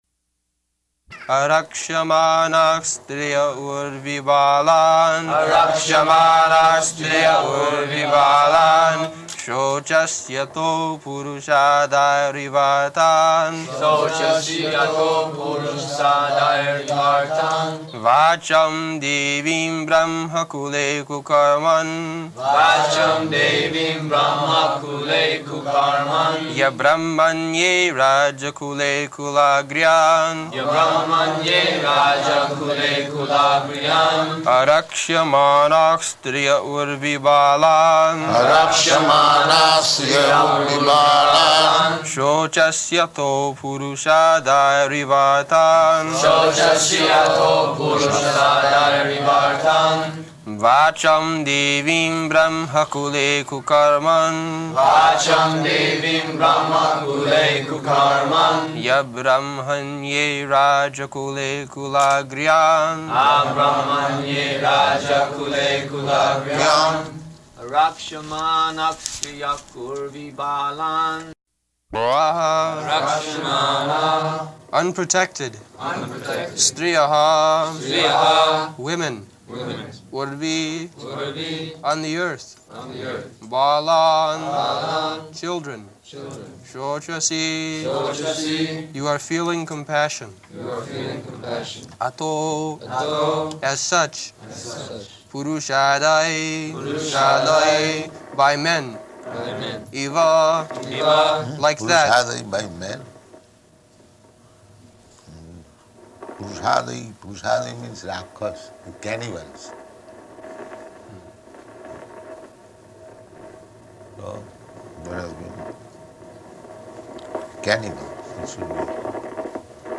Location: Honolulu
[devotees repeat]